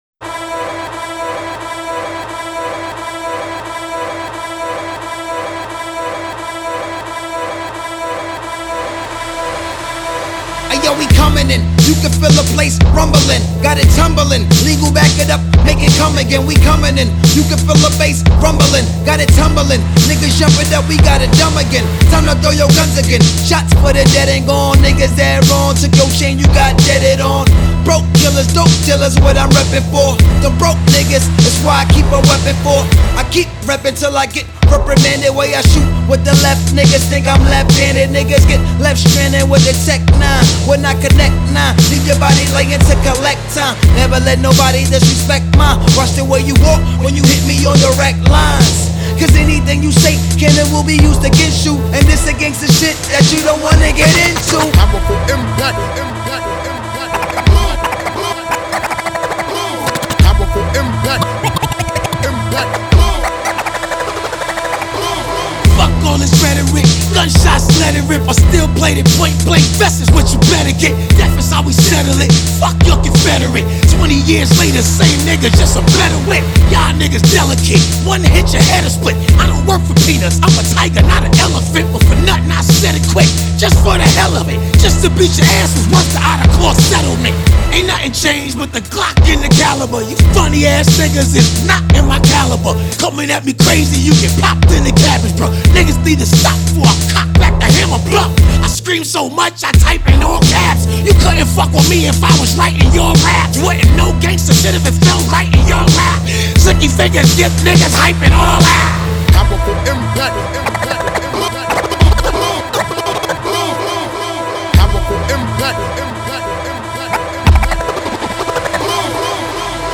Назад в (rap)...